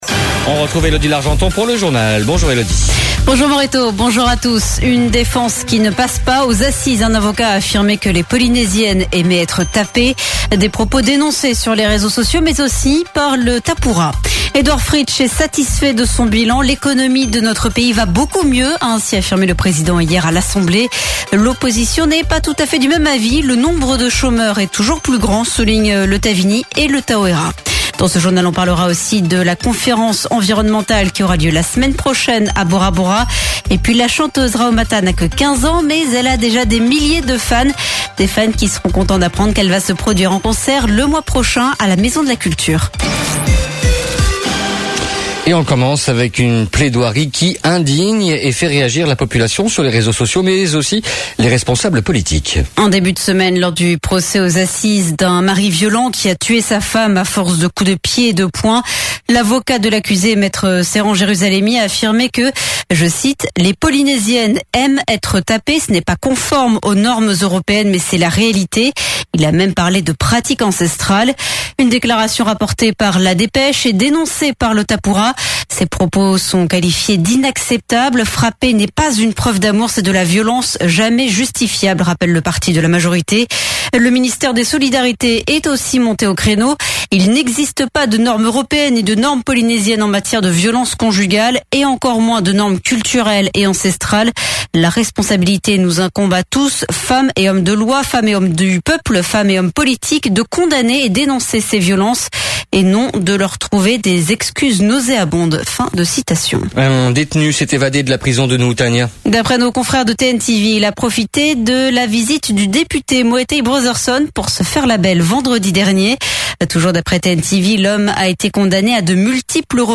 Journal de 7:30 le 22/09/2017